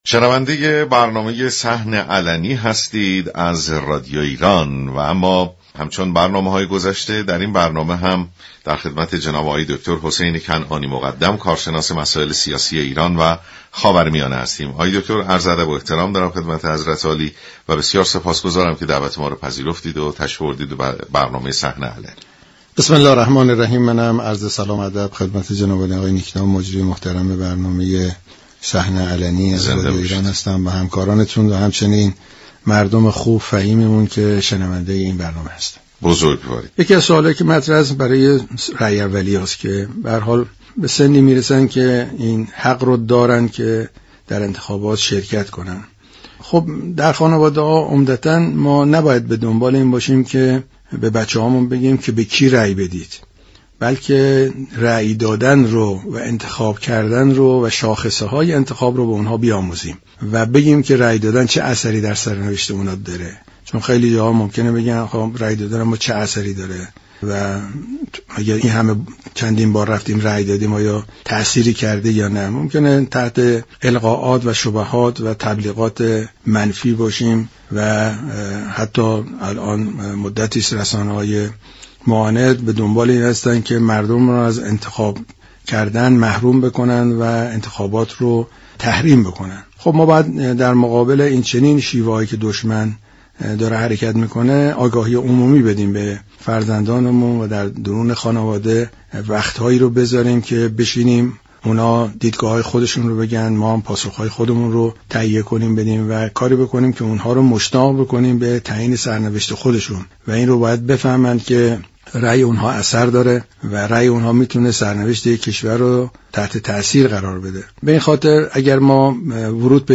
در این خصوص گفت و گو كرده است